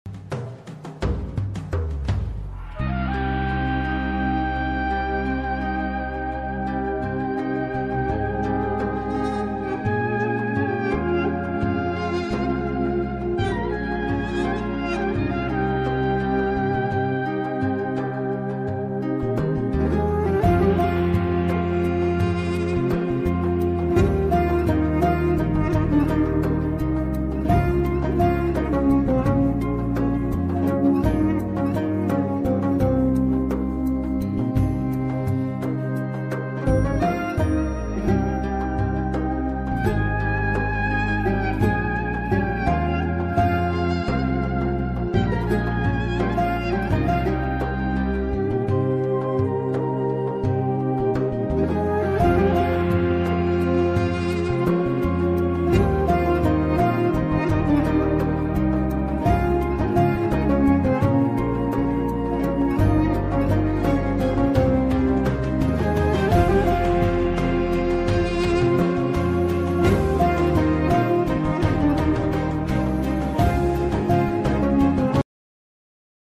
AĞLATAN FON MÜZİĞİ